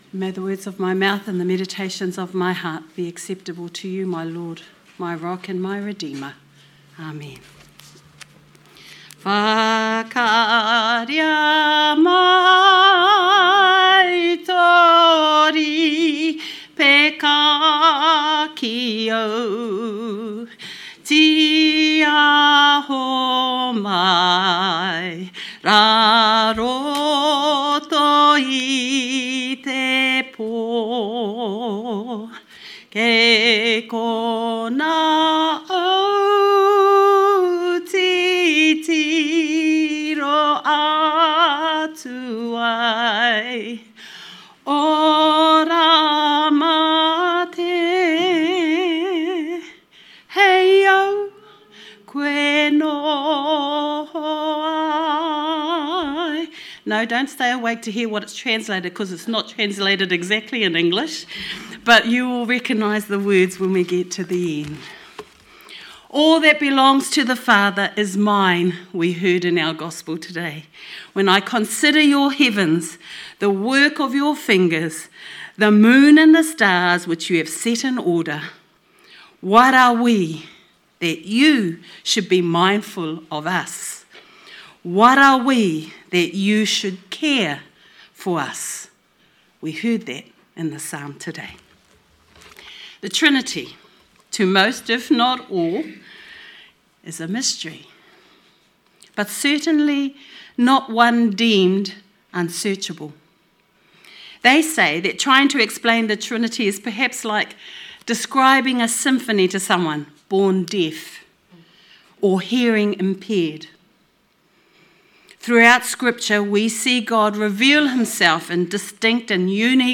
Sermon 15th June 2025